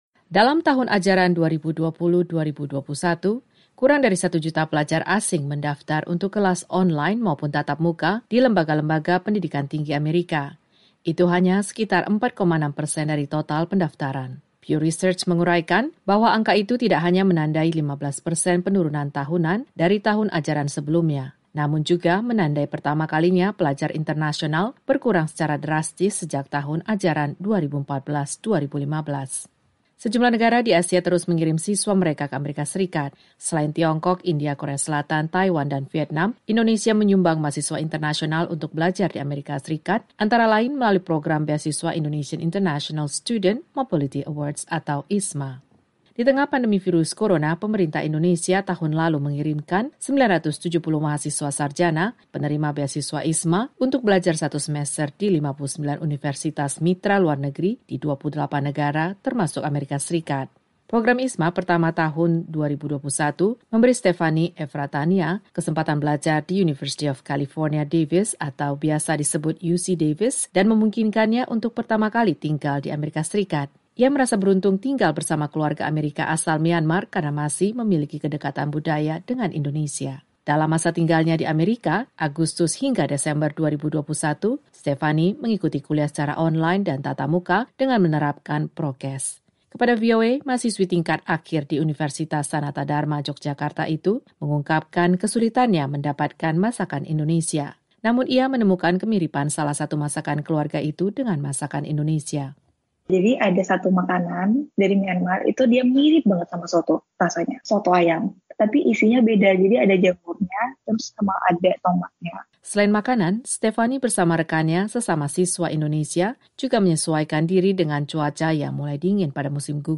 Melalui program IISMA, pemerintah Indonesia mengirimkan sejumlah mahasiswa Indonesia untuk belajar ke luar negeri, termasuk AS pada musim gugur lalu. Berikut penuturan siswa mengenai pengalaman yang mereka peroleh ketika kuliah di tengah pandemi COVID-19.